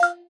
Click (7).wav